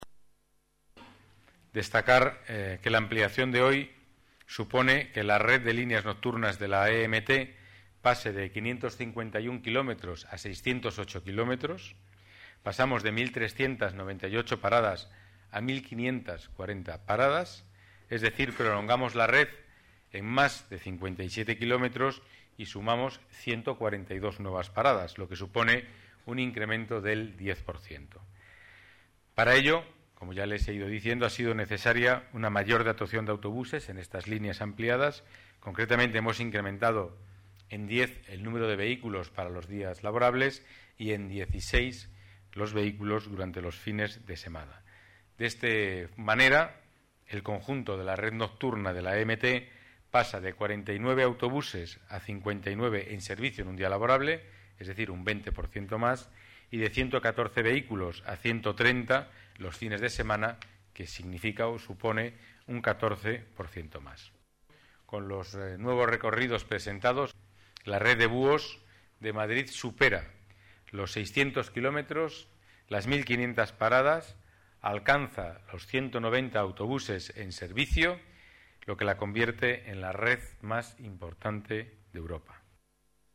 Nueva ventana:Declaraciones del delegado de Seguridad y Movilidad, Pedro Calvo, sobre los nuevos autobuses nocturnos